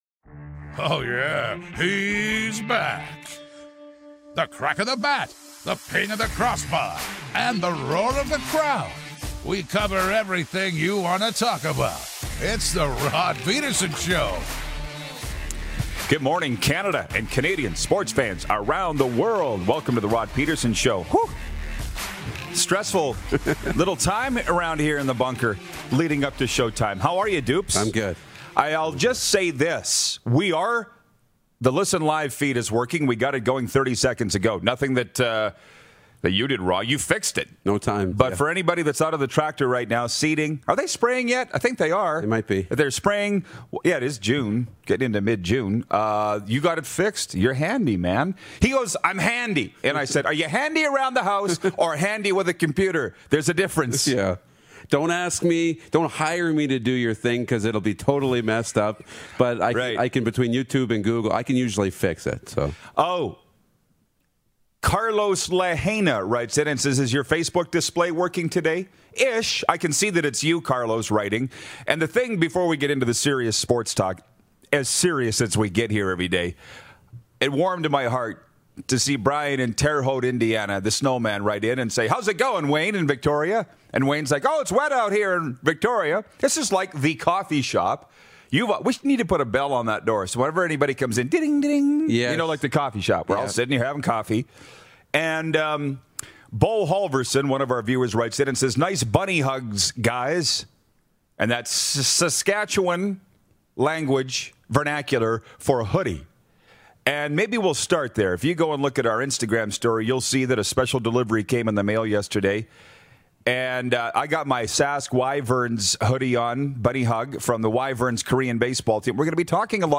Some big news to talk about and some great guests joining in today, so grab your coffee and LET’S GO! Hockey Hall of Famer and 5x Stanley Cup Champion, Mark Recchi, checks in!